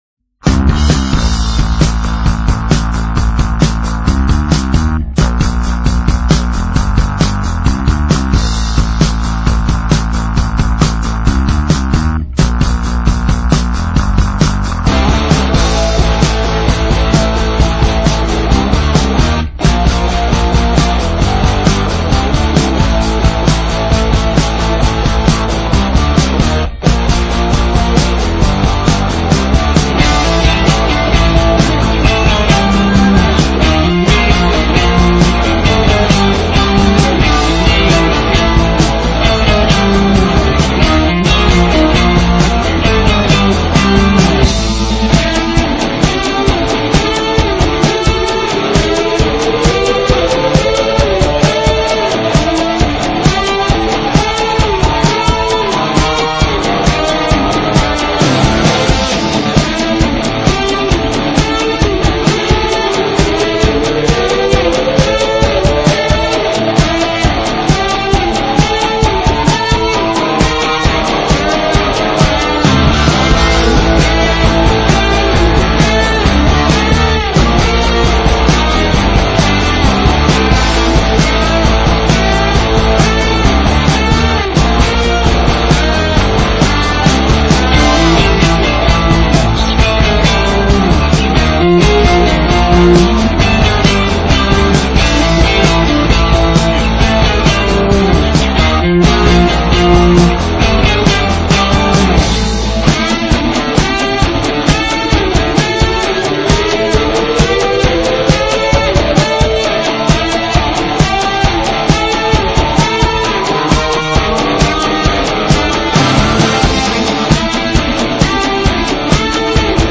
Música de fuga